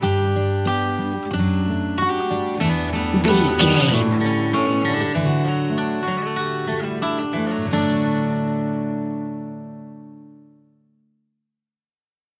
Ionian/Major
D
Slow
acoustic guitar
bass guitar